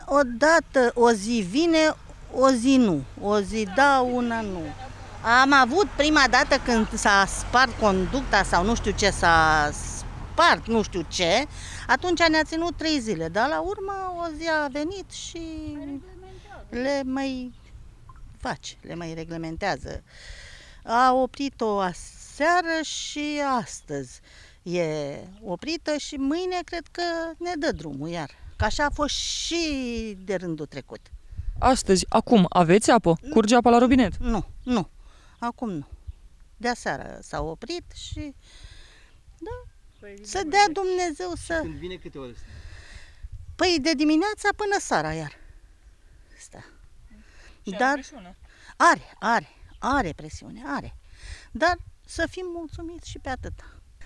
Cetățeni, Comuna Bălănești